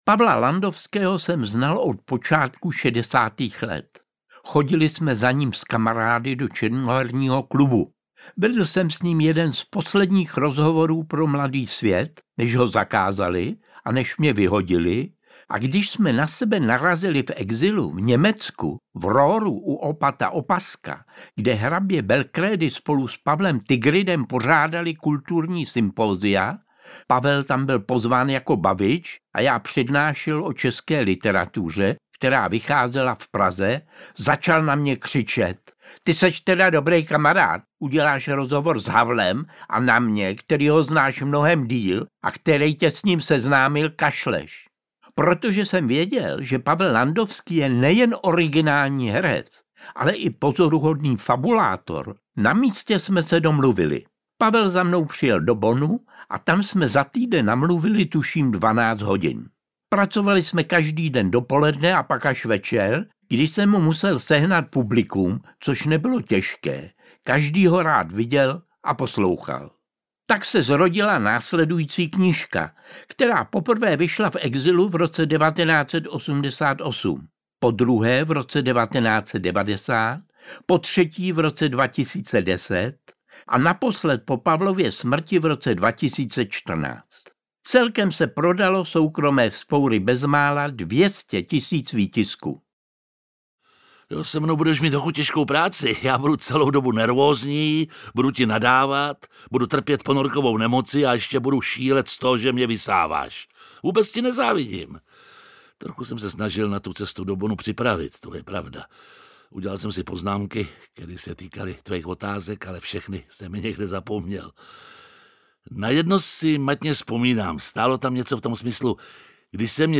Interpret:  Jiří Lábus